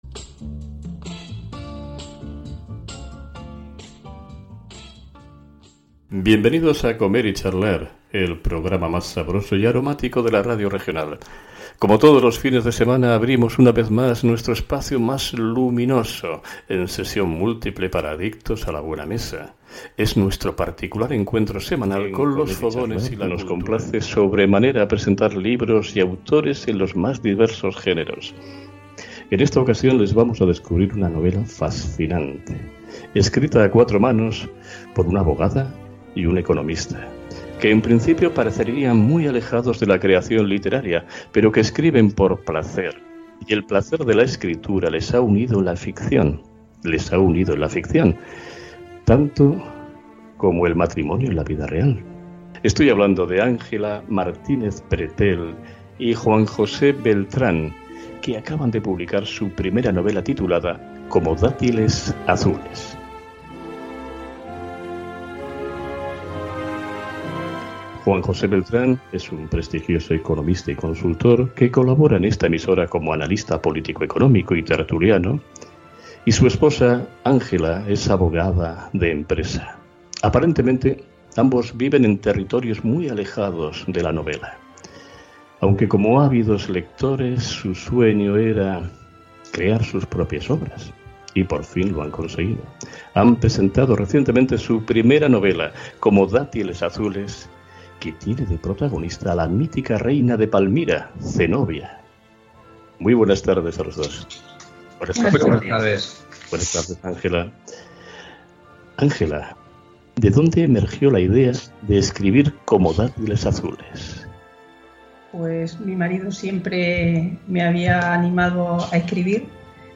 Entrevista en Frecuencia Economica
Entrevista-Como-Datiles-Azules-1.mp3